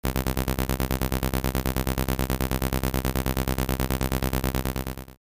Przykłady dźwiękowe uzyskane w wyniku syntezy subtraktywnej oraz ich reprezentacje widmowe:
Efekty filtracji wyjściowego dźwięku